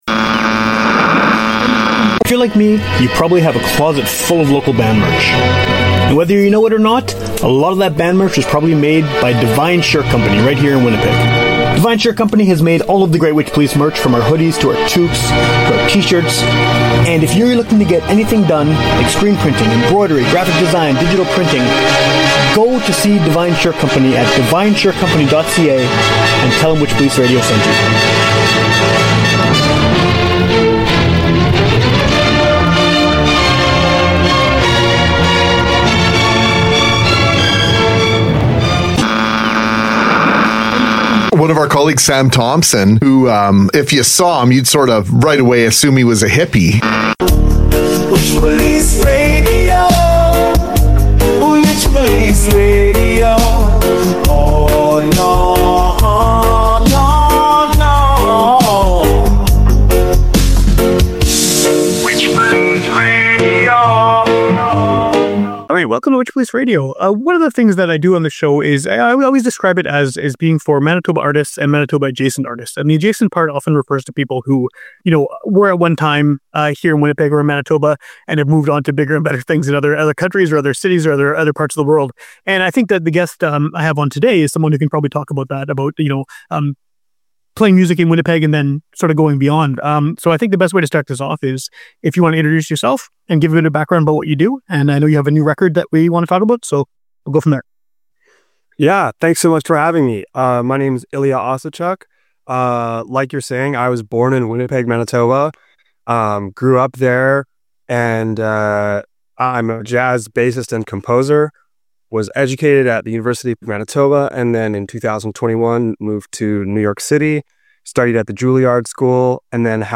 It's a conversation